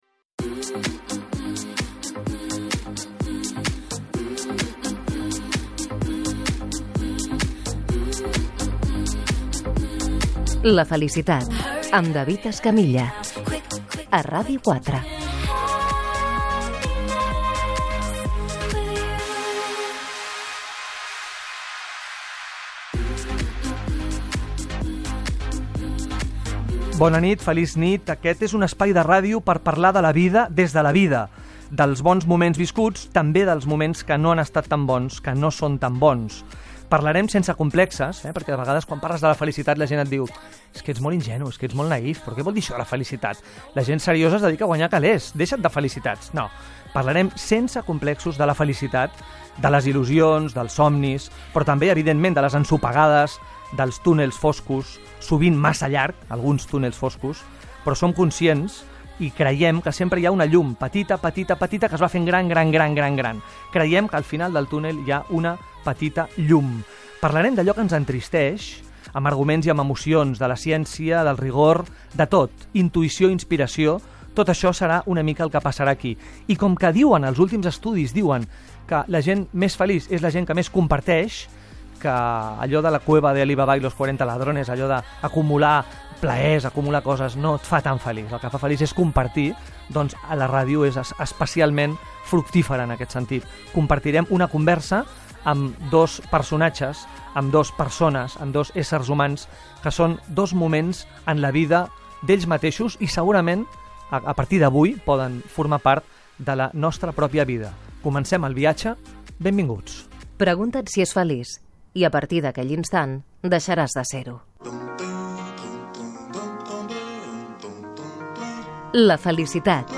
Careta del programa, presentació, indicatiu, equip, entrevista al dibuixant i dissenyador Javier Mariscal
Entreteniment